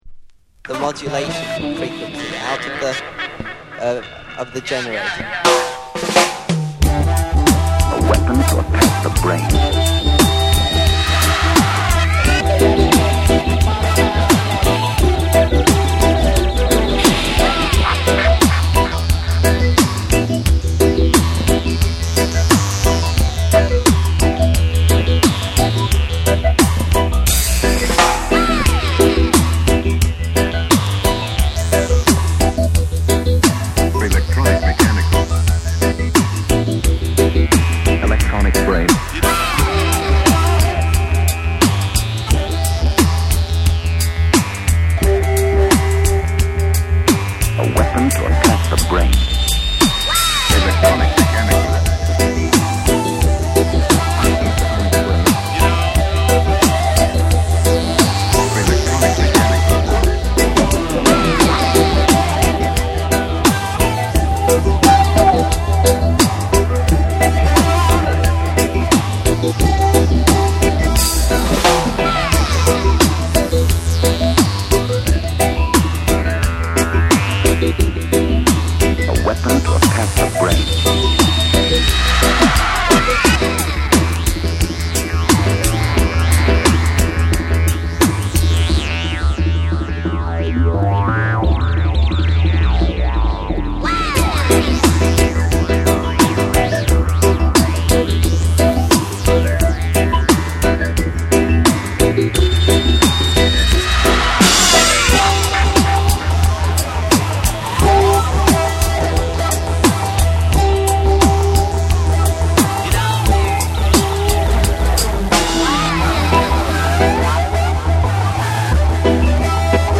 JAPANESE / REGGAE & DUB